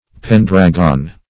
Pendragon \Pen"drag*on\, n.